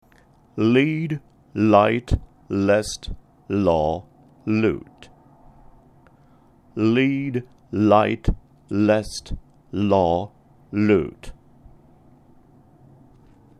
音声サンプル（lead, light, lest, law, lootの順に、2回繰り返す。カナ縛り発音の例は今回省略する）
PronunciationSamplesL.mp3